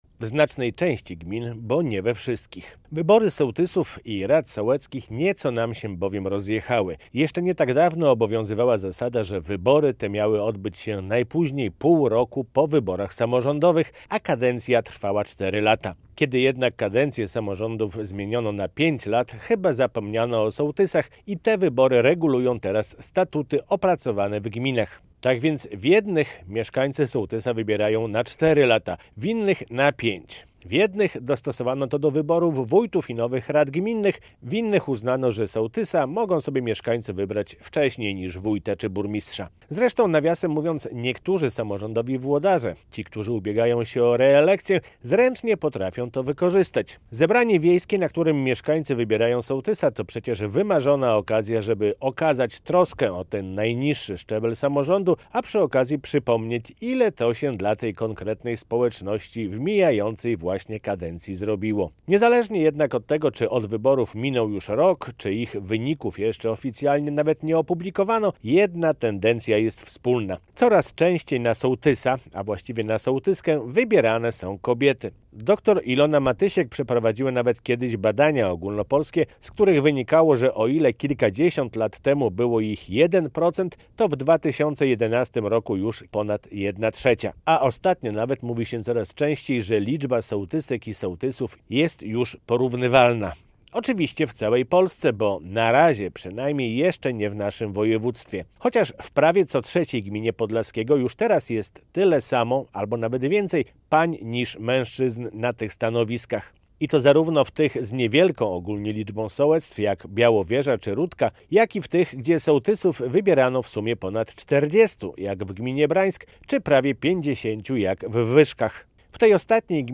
Szefowe wsi - felieton